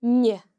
The slender nn can be heard in linn (a century):